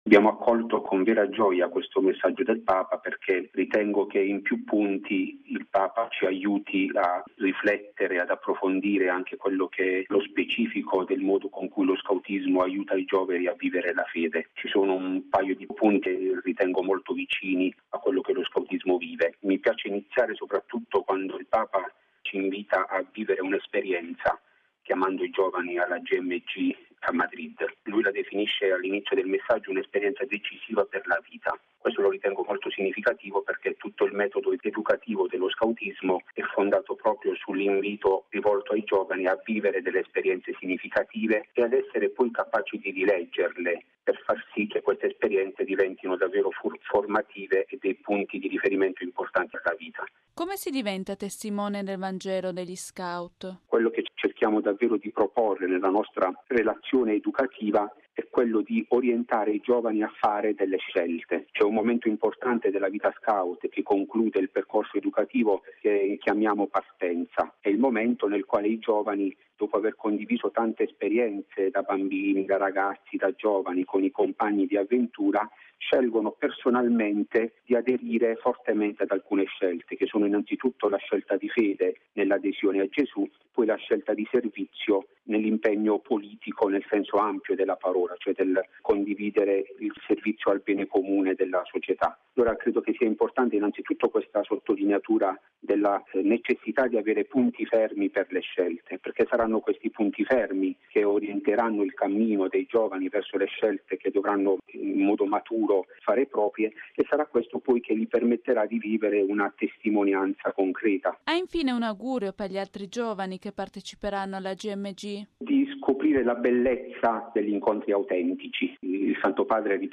La presenza degli scout alla Gmg